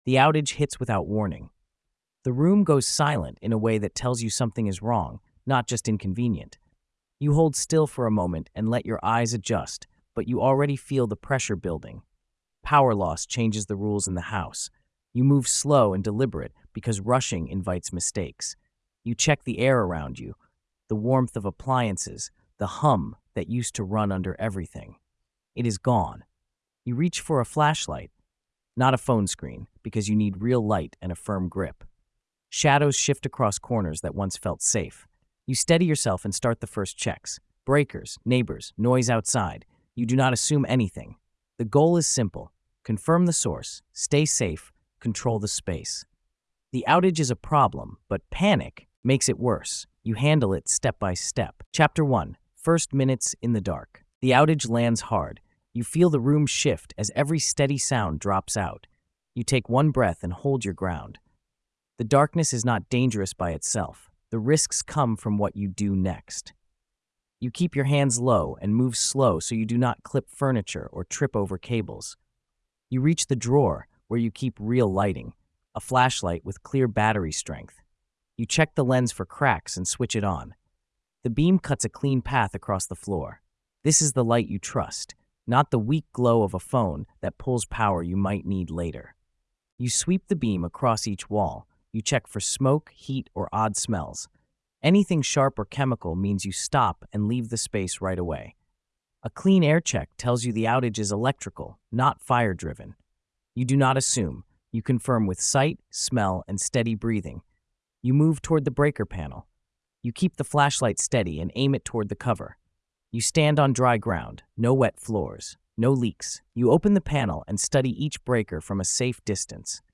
You learn how to confirm the outage, inspect breakers without risk, test circuits, stabilize the home, protect food, and manage light and movement in a dark environment. The tone stays sharp and grounded, showing you how to think under pressure and hold control until the grid returns or help arrives.